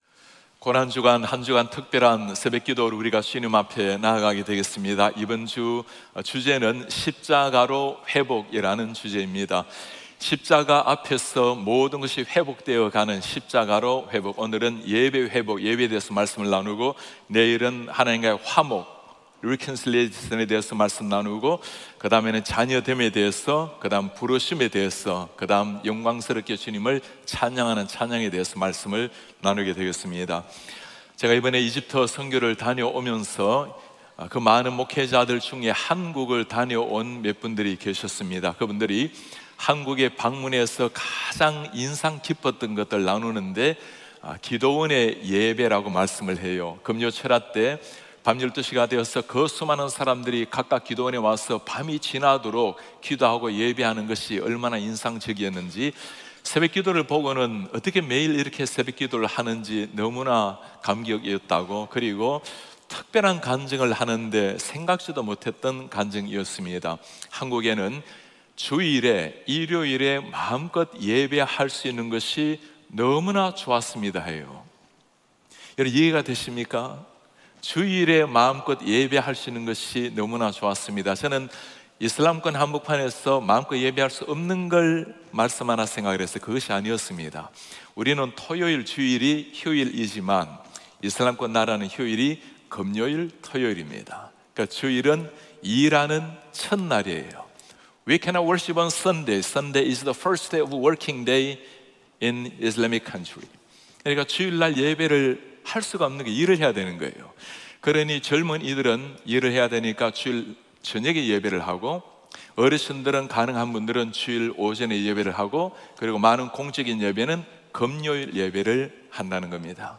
2024년 고난주간 특별새벽기도회 첫째날 | 십자가로의 회복 – 예배 (3/25/2024)